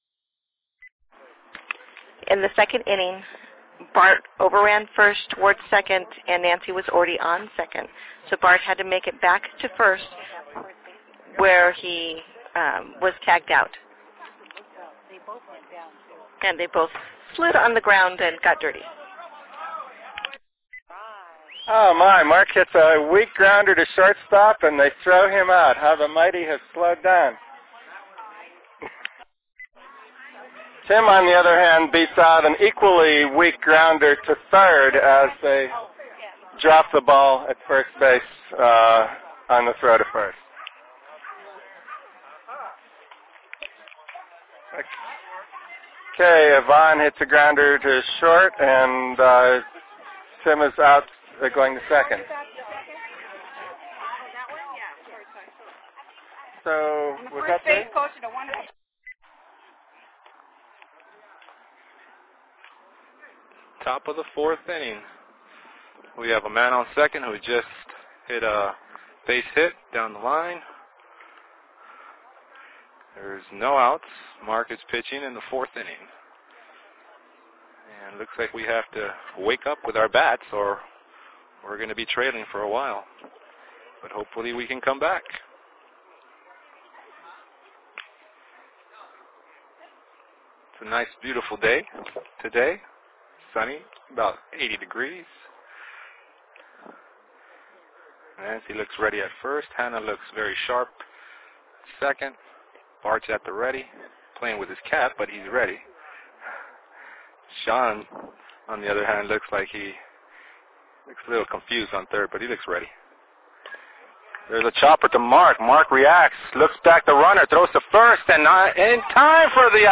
Play-by-Play Audio *To read the play-by-play game summary